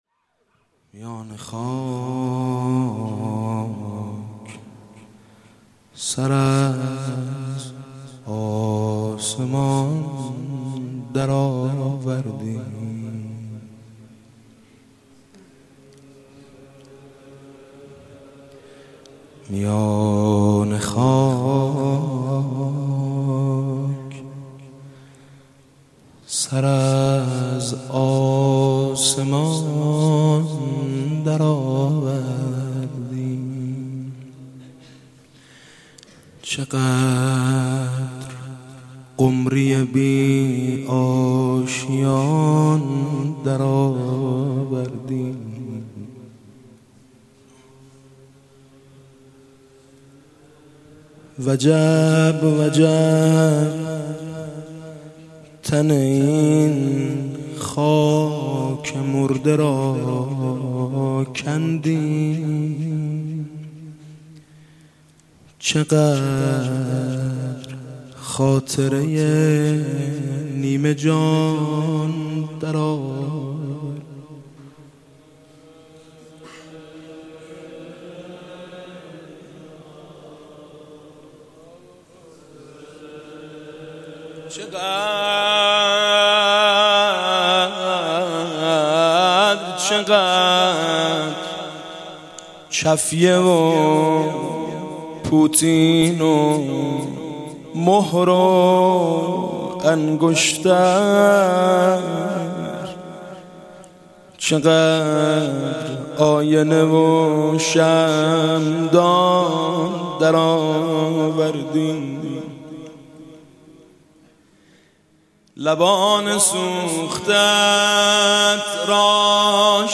غزلی از سعید بیابانکی با صدای میثم مطیعی
سعید بیبانکی،میثم مطیعی،غزل،مداحی